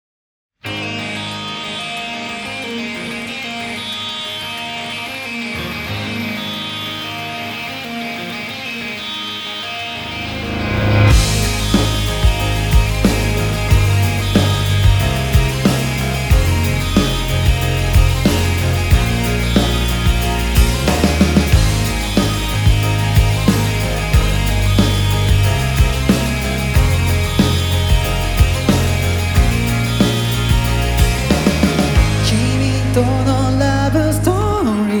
J-Pop
Жанр: Поп музыка